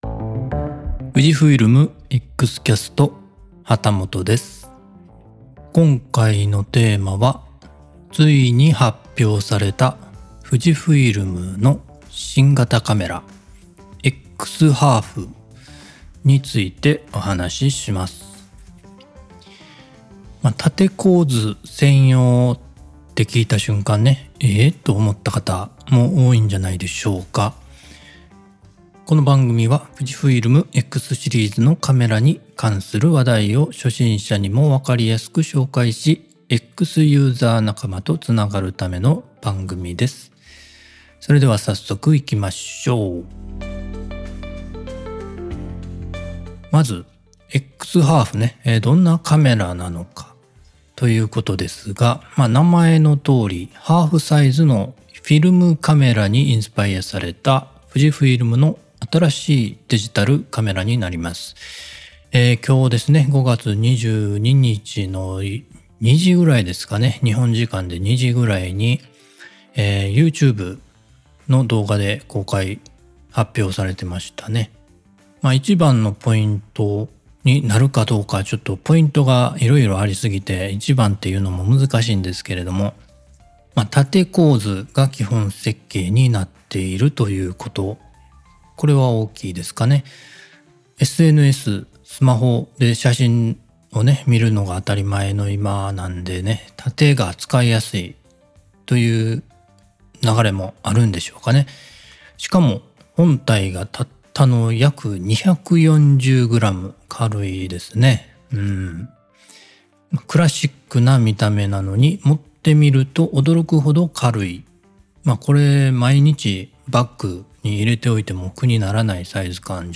Audio Channels: 2 (stereo)